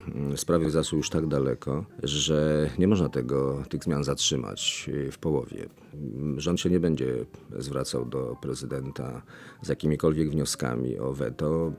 Mówi premier, Leszek Miller